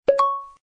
slot_unlock.ogg